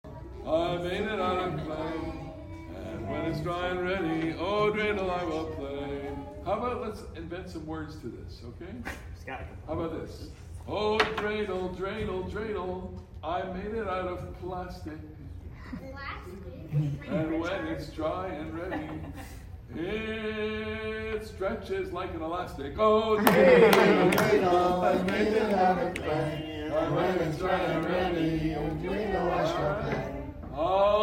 Hanukkah Fun
PAINT & SIP event for young children